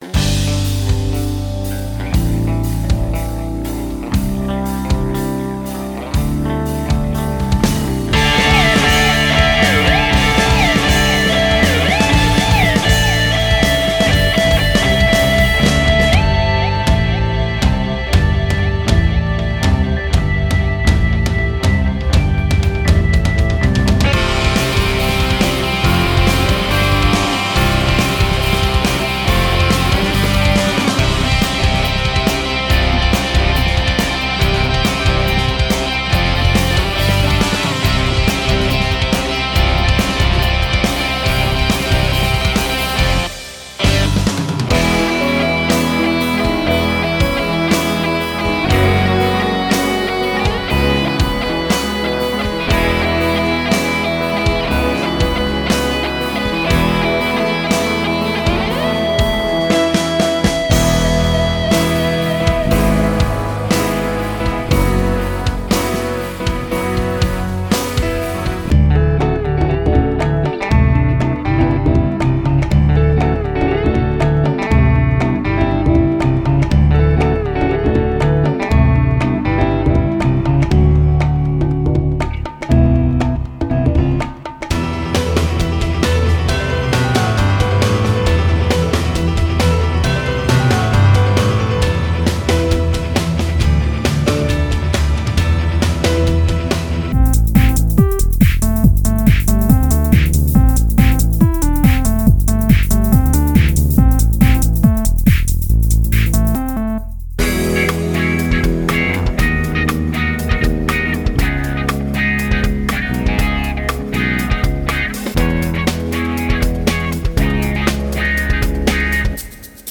Подборка фрагментов музыки, которую я написал для разных заказов